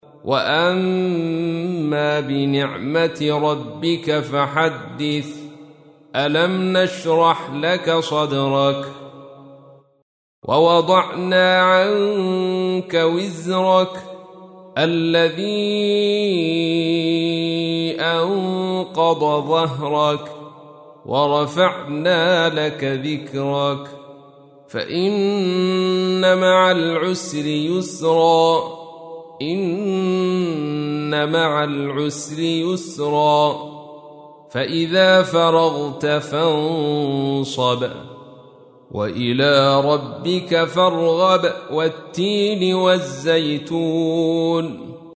تحميل : 94. سورة الشرح / القارئ عبد الرشيد صوفي / القرآن الكريم / موقع يا حسين